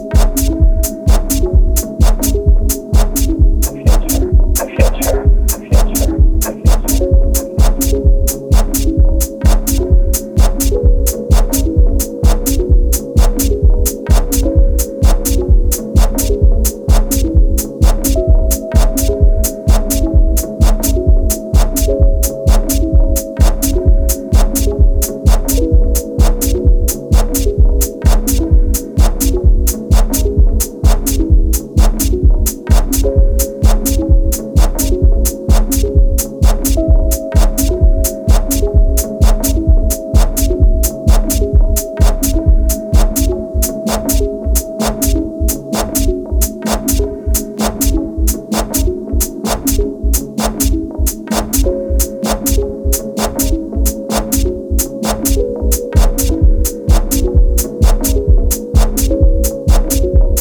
deep, mesmerizing sounds and captivating atmospheres
House Techno Dub Techno